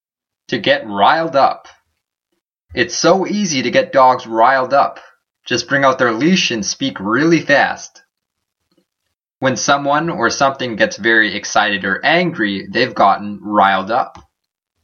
注意しなくてはならないのは、発音で、ráil と発音します。英語ネイティブによる発音は下記のリンクをクリックしてください。